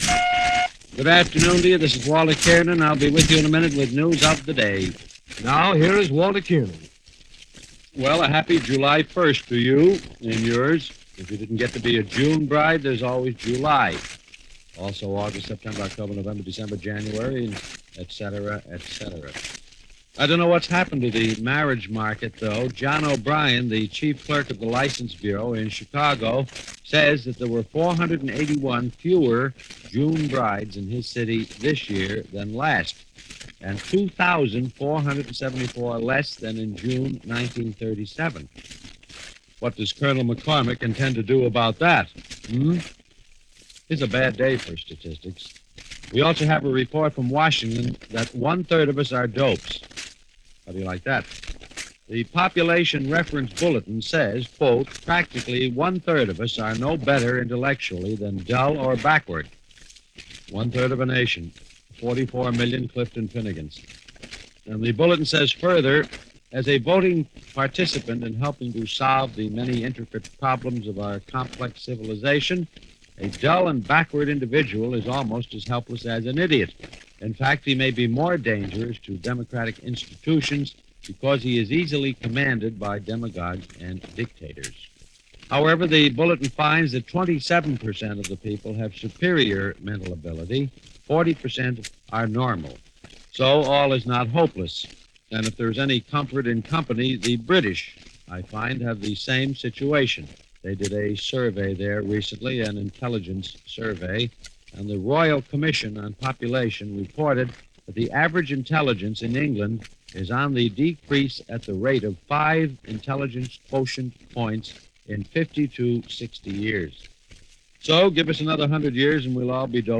-July 1, 1947 – ABC Radio – Walter Kiernan News and Commentary
In this case, a commentary on news of the day by noted broadcaster and essayist Walter Kiernan from July 1, 1947 in which he reveals, much to his somewhat jaundiced amazement, that recent studies concluded fully 1/3 of Americans were considered less-than-intelligent/easily conned and painfully unaware of the world around them.
ABC-Radio-Walter-Kiernan-July-1-1947.mp3